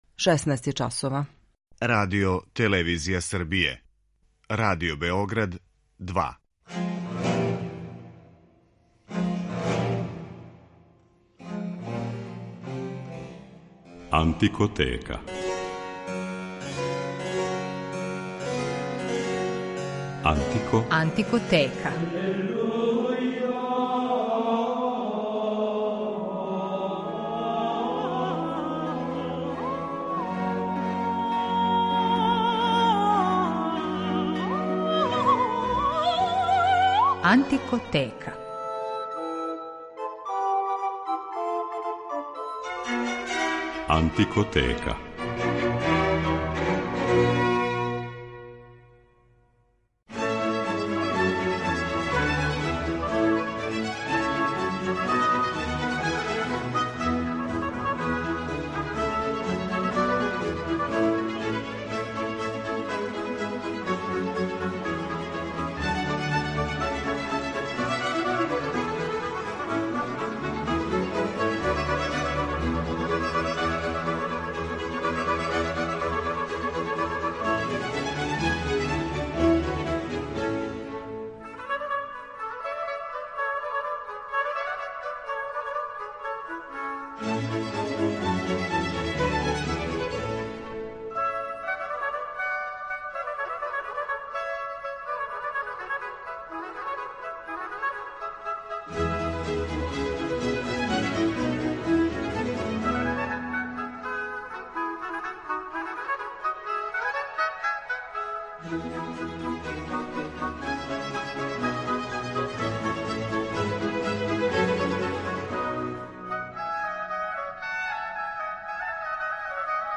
Њихове концерте за фагот, обоу д`аморе, ловачки рог и шалмај моћи ћете данас да слушате. У рубрици „Антикоскоп" представићемо један од најнеобичнијих инструмената у читавој историји, који припада породици гудачких инструмената, али који звучи као труба.